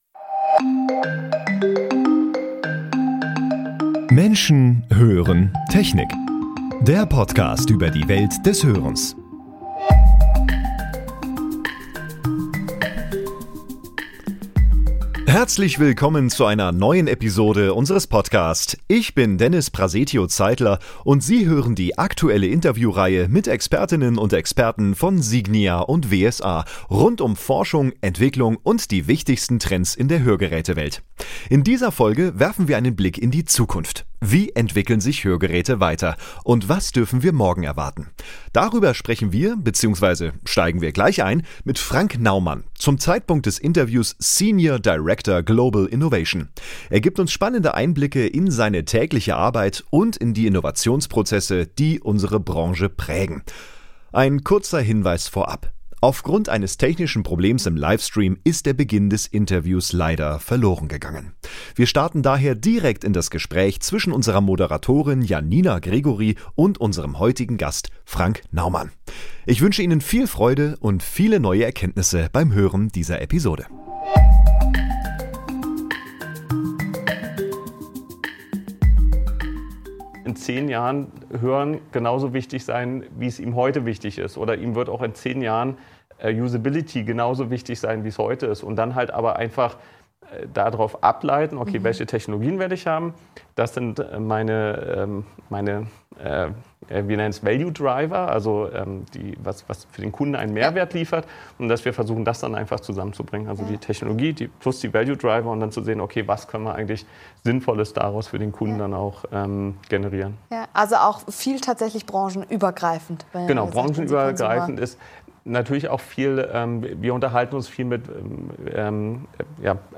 Aufgrund eines technischen Problems ist der Beginn des Interview verloren gegangen.